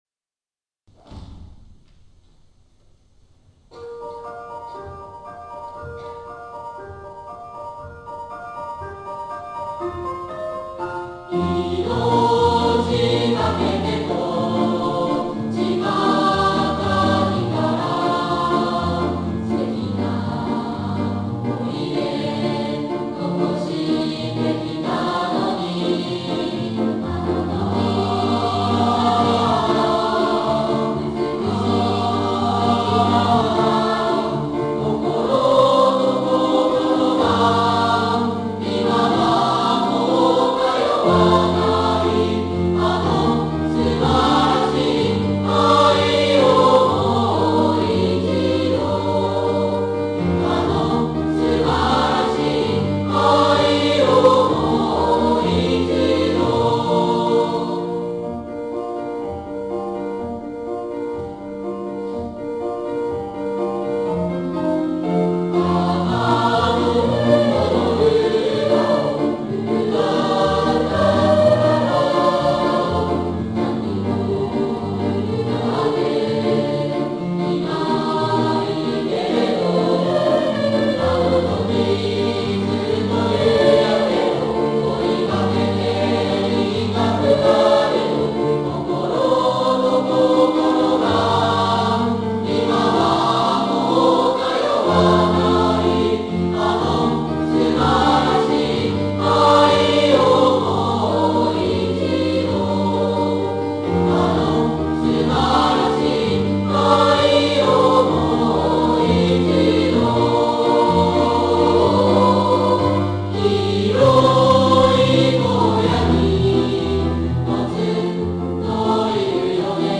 ～校内合唱コンクールが行われました（11月12日）～
芸術の秋，この日のために朝夕となく練習を重ねてきた中学生・高校生の歌声が，学内に響きわたりました。